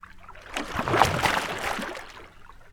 Water_49.wav